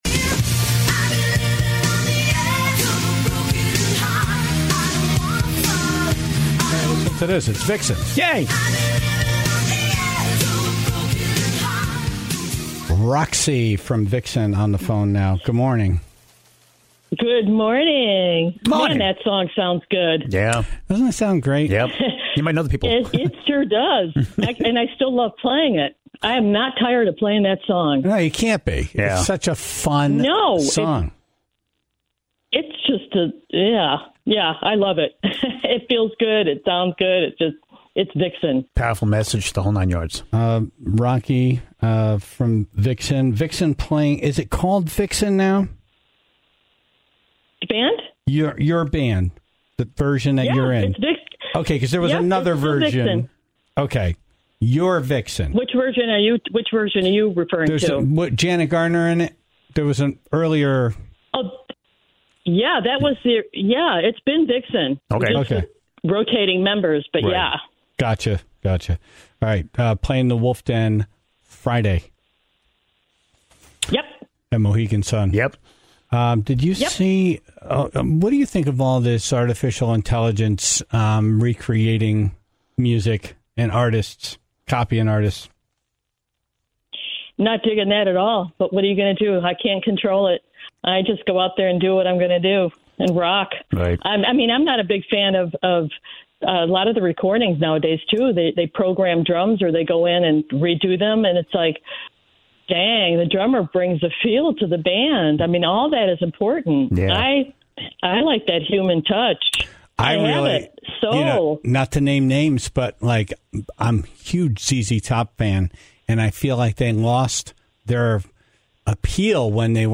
on the phone this morning